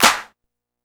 OZ-Clap 7.wav